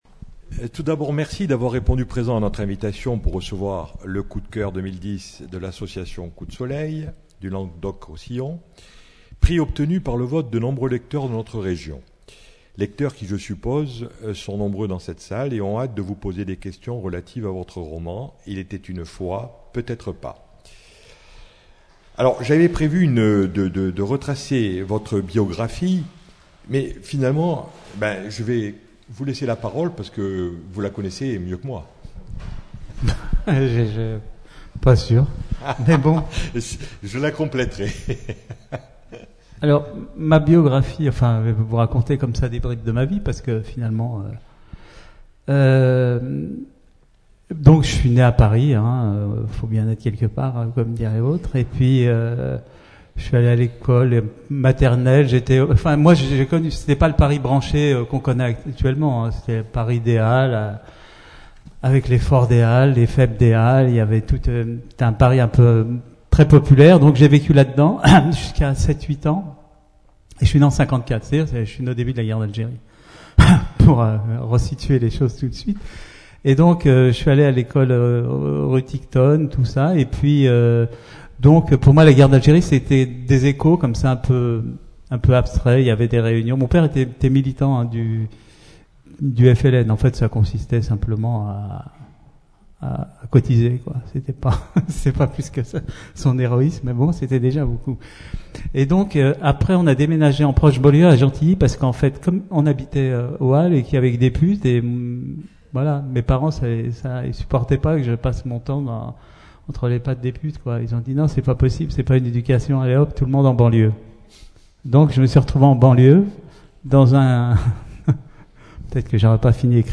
Tadjer, Akli. Personne interviewée
Rencontre littéraire